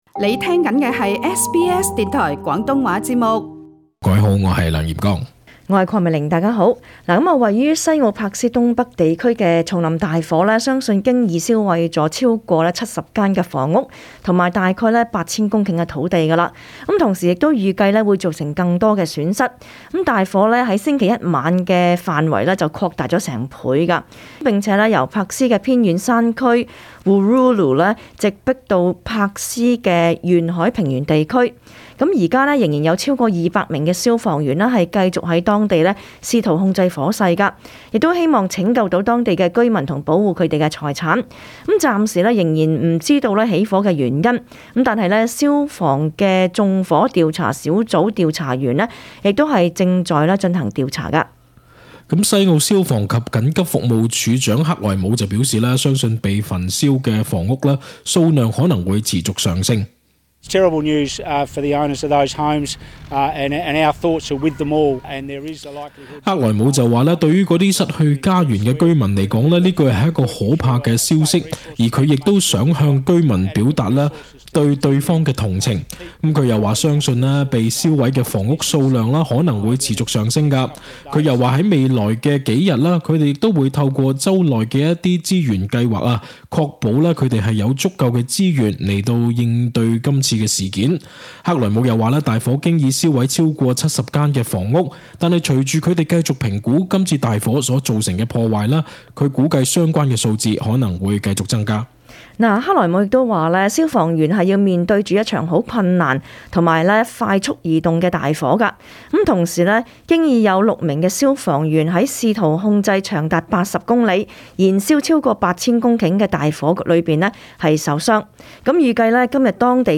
Source: DFES SBS广东话播客 View Podcast Series Follow and Subscribe Apple Podcasts YouTube Spotify Download (9.9MB) Download the SBS Audio app Available on iOS and Android 位于西澳柏斯东北地区的丛林大火，相信经已烧毁了超过七十间房屋和约8000公顷土地。